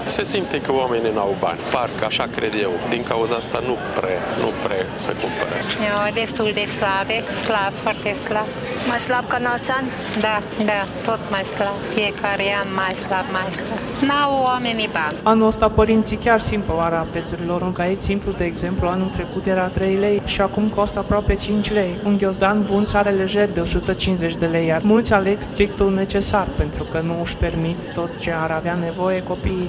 Și vânzătorii se plâng de o scădere a veniturilor și spun că părinții cumpără doar strictul necesar și puțini sunt cei care își permit rechizite scumpe.